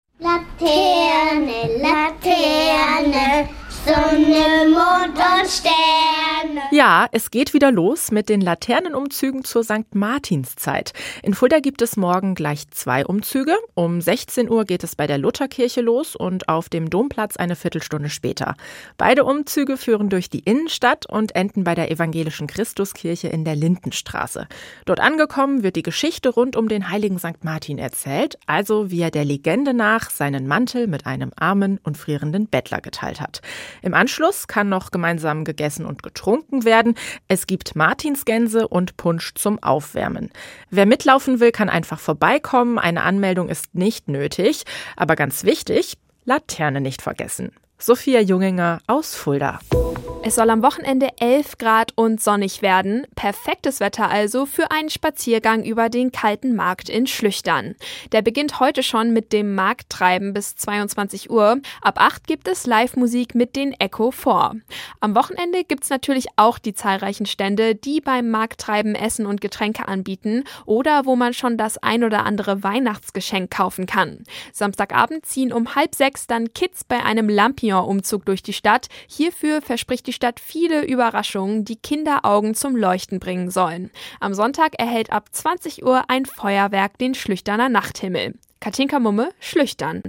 Tägliche Nachrichten Nachrichten hessenschau
Mittags eine aktuelle Reportage des Studios Fulda für die Region